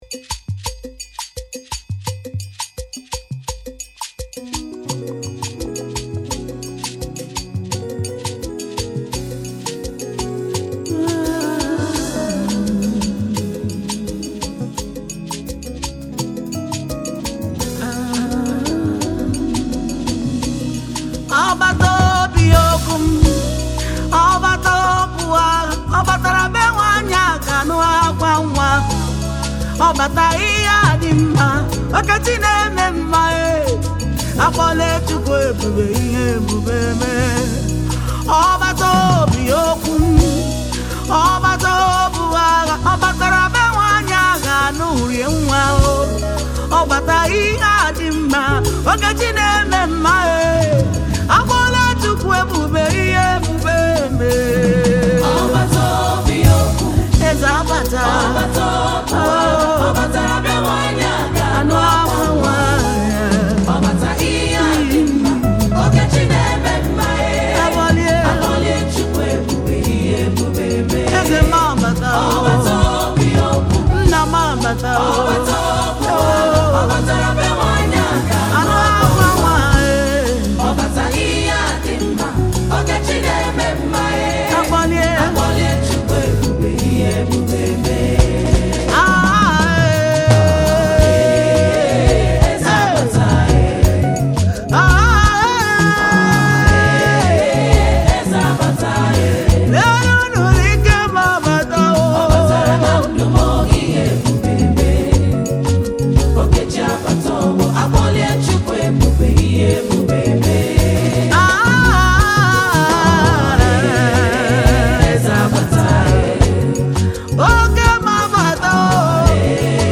Igbo Gospel Music
soul-stirring new single
With its uplifting beats and inspiring lyrics